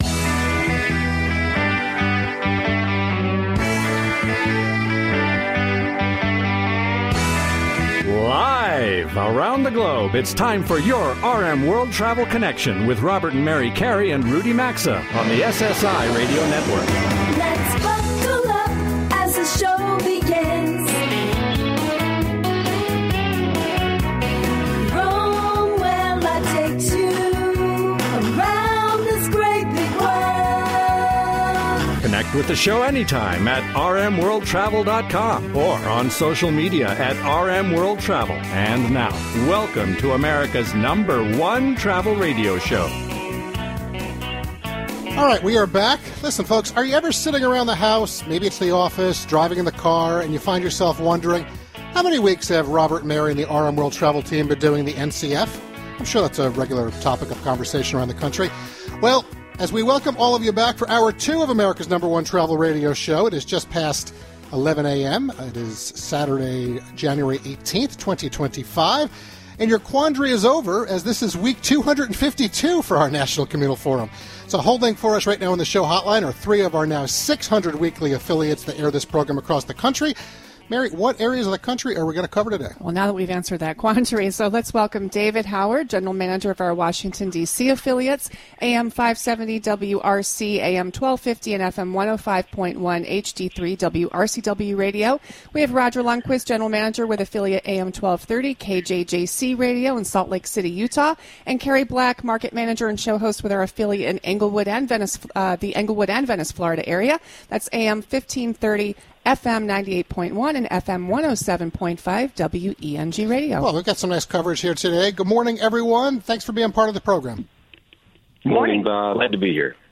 America's #1 Travel Radio Show
They provide straight-forward advice and commentary, inside scoop, tips/trends and more, as they connect with the audience and skillfully cover the world of travel, culture, and its allure through modern segments, on-location remote broadcasts, on-air showcasing and lively banter.